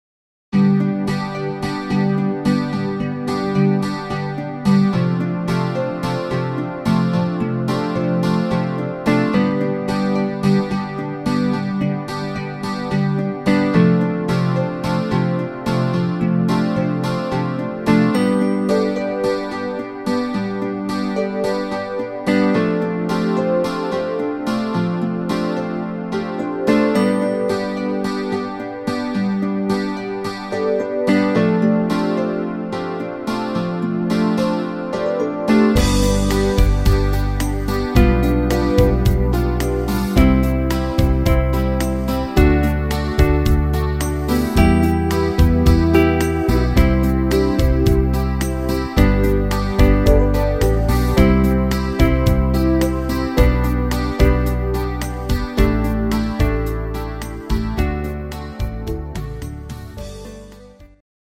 (instr. Gitarre)